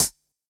Index of /musicradar/ultimate-hihat-samples/Hits/ElectroHat D
UHH_ElectroHatD_Hit-29.wav